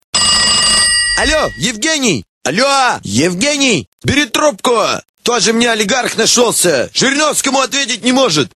Категория: Именные рингтоны